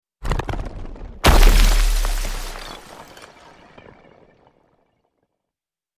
Звуки метеорита
Комета распадается на фрагменты (для монтажа)